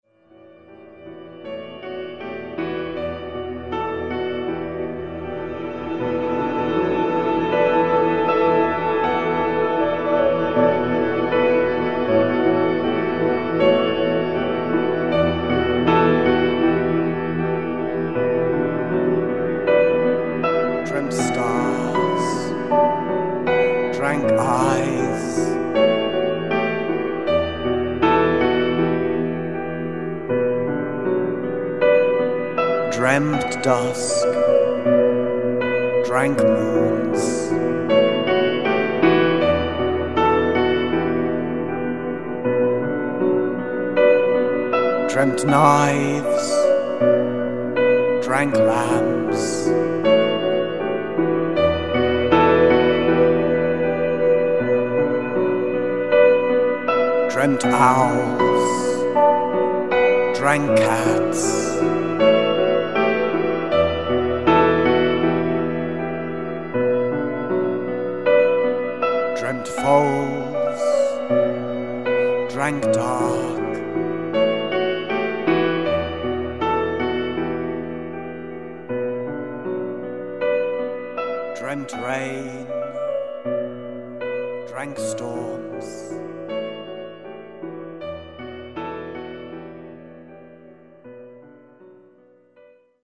vocals
electric rain
piano